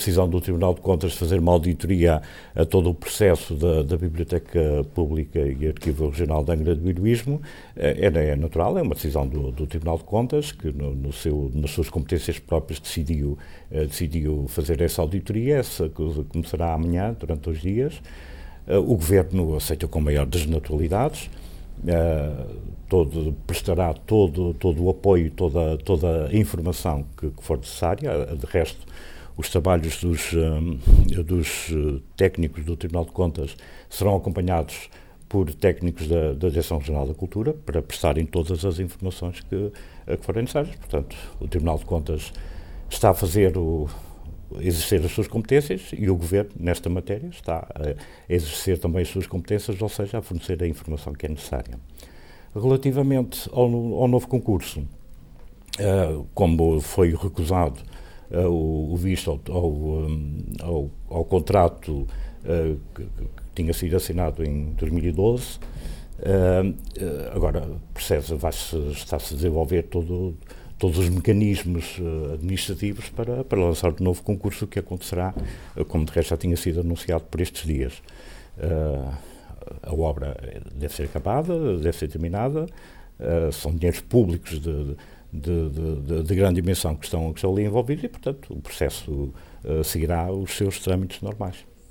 O Secretário Regional, interpelado pelos jornalistas no final da cerimónia de assinatura de um protocolo com a Diocese de Angra, reafirmou que será lançado nos próximos dias o novo procedimento administrativo com vista a ultrapassar a recente recusa de visto por parte do Tribunal de Contas para a fase final de obras da nova Biblioteca Pública de Angra do Heroísmo.